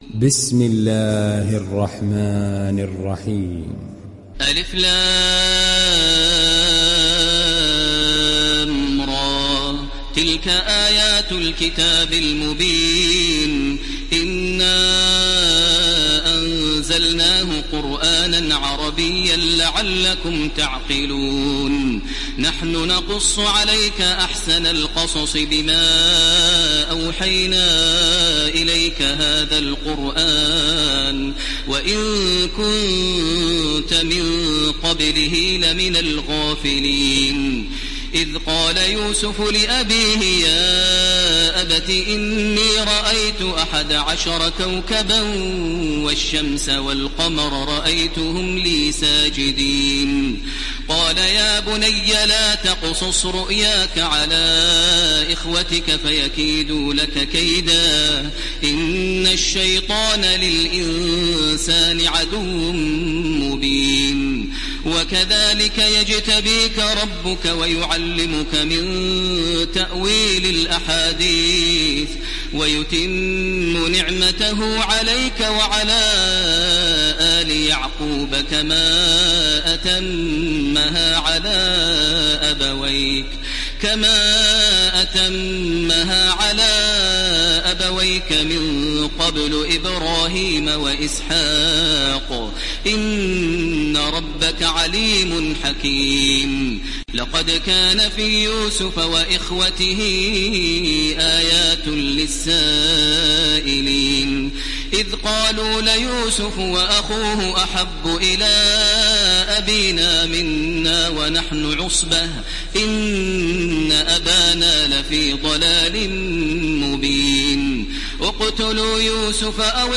İndir Yusuf Suresi Taraweeh Makkah 1430